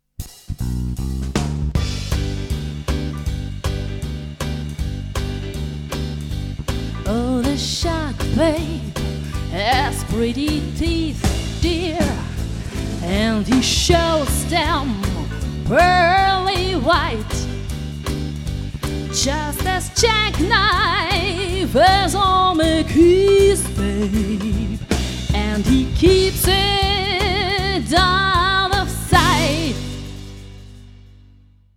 (für die Kirche)